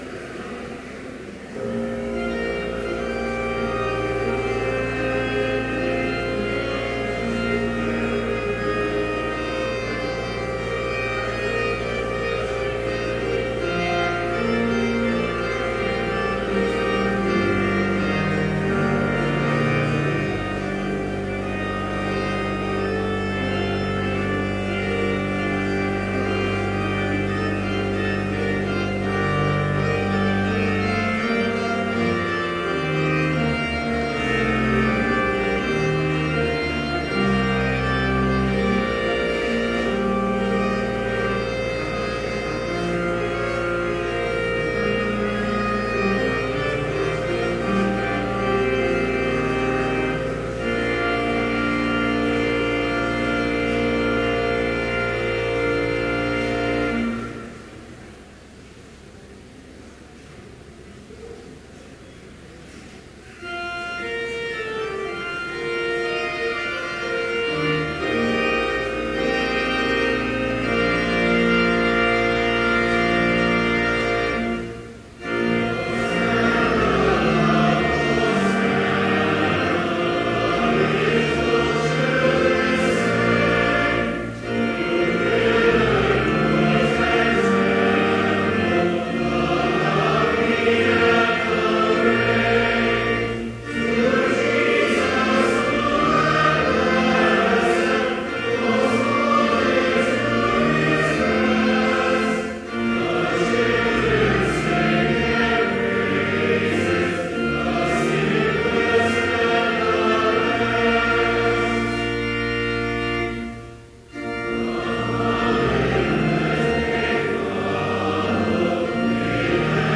Complete service audio for Chapel - April 14, 2014
Prelude Hymn 279, Hosanna, Loud Hosanna Reading: John 12:12-18 Homily Prayer Hymn 280, Ride On, Ride On, in Majesty Benediction Postlude